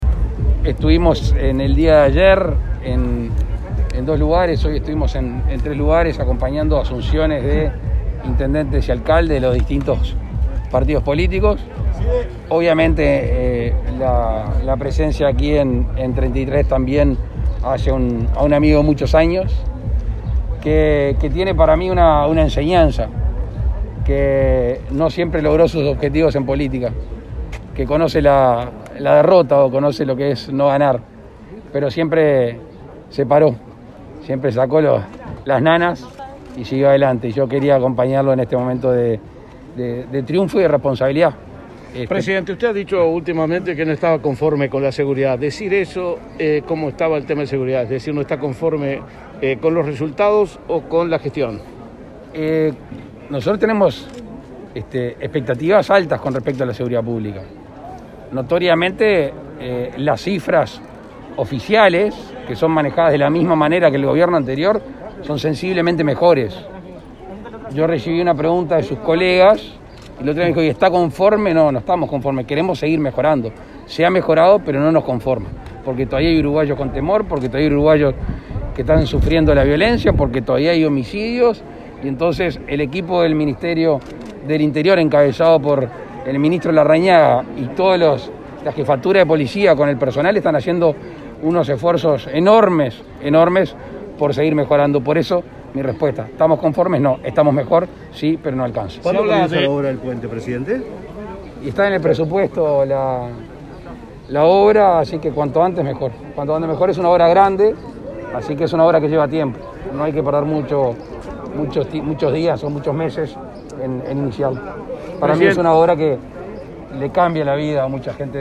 Presidente Luis Lacalle Pou participó en la asunción del intendente de Treinta y Tres, Mario Silvera
La recorrida por el interior del presidente de la República, Luis Lacalle Pou, finalizó en la tarde del viernes 27 con su participación en la ceremonia de asunción del intendente de Treinta y Tres, Mario Silvera, en una ceremonia que se desarrolló en el Teatro de Verano de la capital olimareña. Confirmó la construcción del puente que une La Charqueada con Cebollatí, obra presupuestada para esta administración.